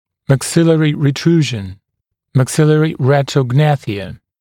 [mæk’sɪlərɪ rɪ’truːʒn][мэк’силэри ри’тру:жн]ретрогнатия верхней челюсти